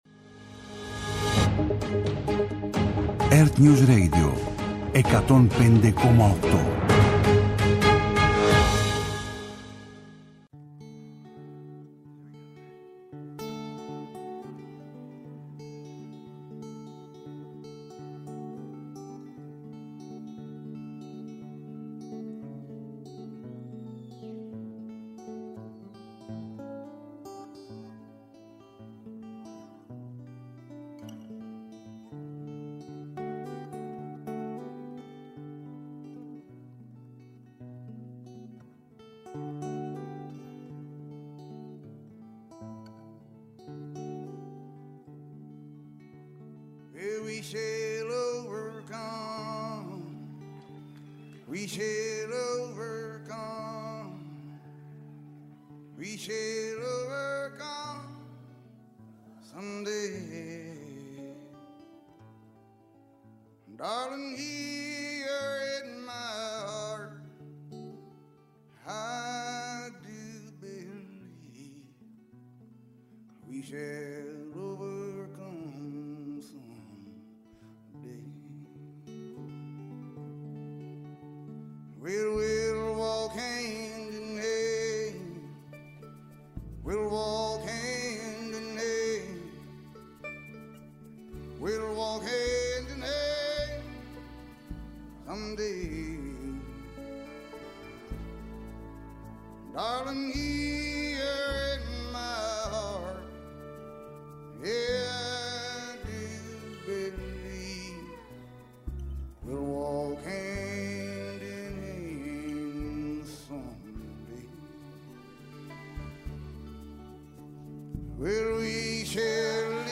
-Σύνδεση με ERTNEWS για τη μετάδοση των δηλώσεων της Εκπροσώπου Τύπου του Λευκού Οίκου, Καρολάιν Λέβιτ
-Σύνδεση με ERTNEWS για την απευθείας μετάδοση του Διαγγελματος του Νίκου Χριστοδουλίδη, Προέδρου της Κυπριακής Δημοκρατίας